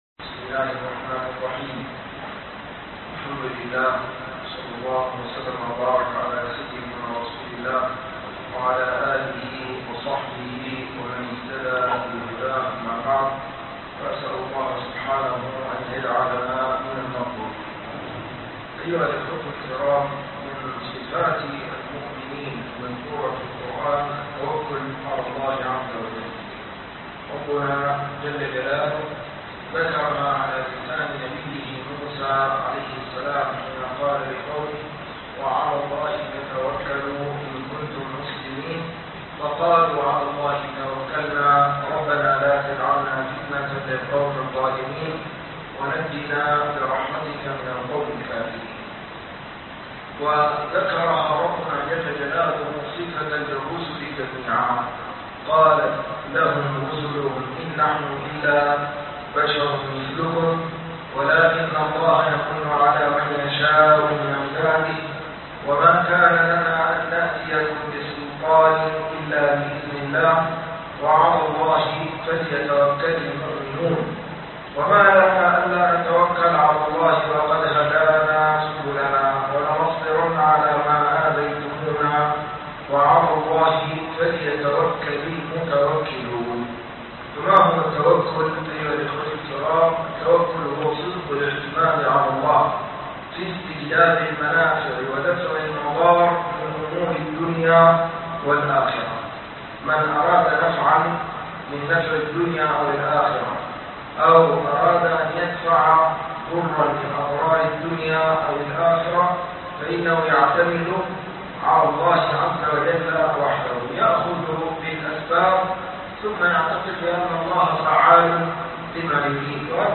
صفات المؤمنين فى القرآن الكريم (التوكل) - دروس التراويح - الشيخ عبد الحي يوسف عبد الرحيم